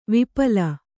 ♪ vipala